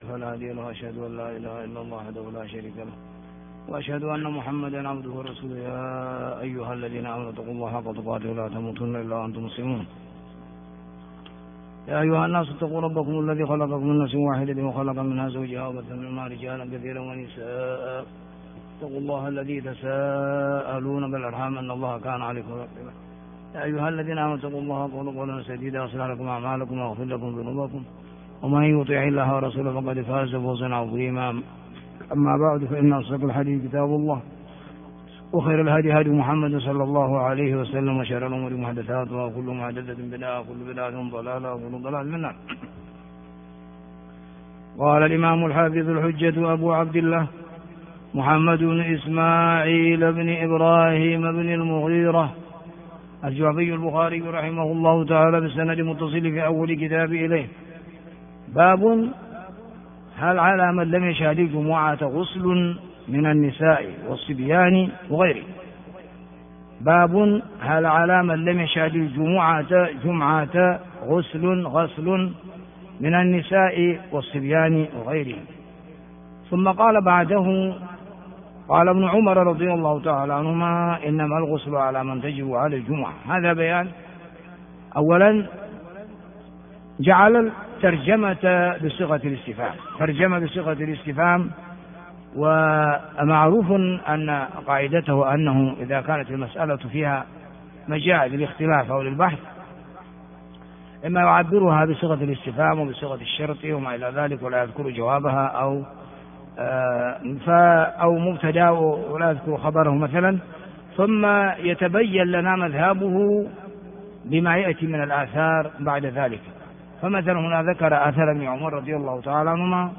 الدرس 074 - كتاب الجمعة - بَابُ هَلْ عَلَى مَنْ لَمْ يَشْهَدِ الجُمُعَةَ غُسْلٌ مِنَ النِّسَاءِ وَالصِّبْيَانِ وَغَيْرِهِمْ؟- ح 894